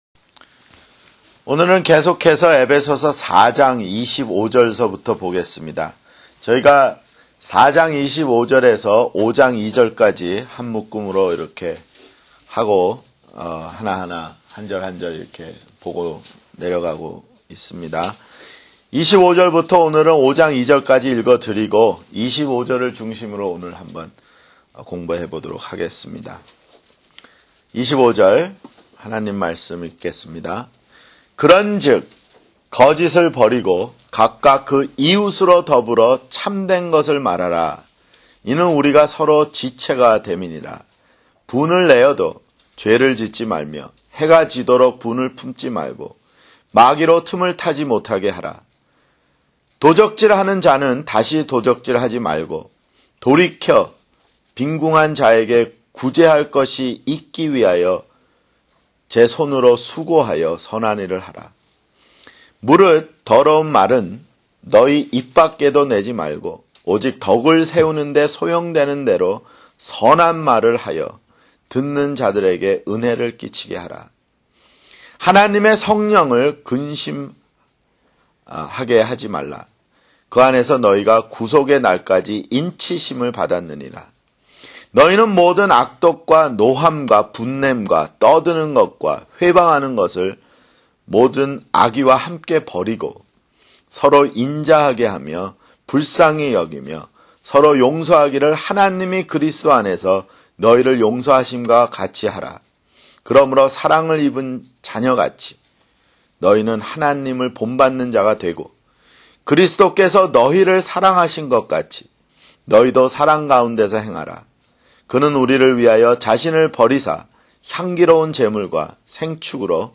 [성경공부] 에베소서 (52)